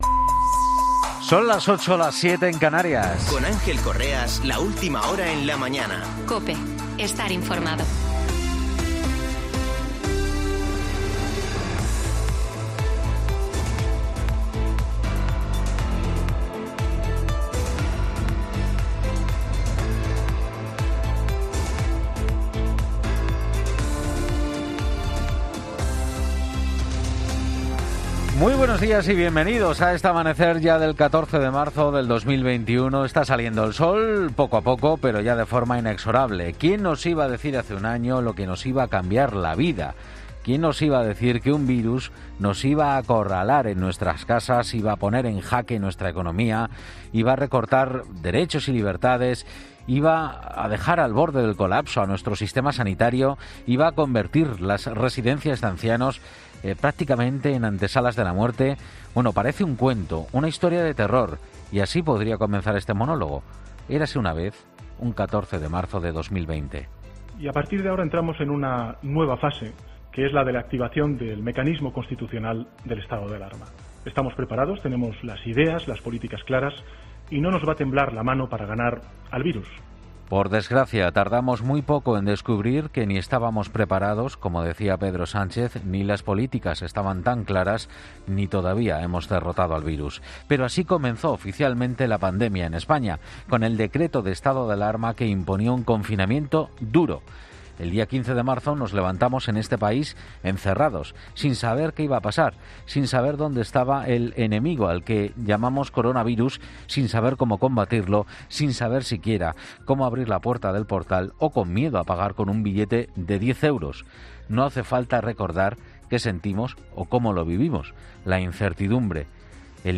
AUDIO: Ya puedes escuchar el monólogo del comunicador en 'La Mañana Fin de Semana' de este domingo 14 de marzo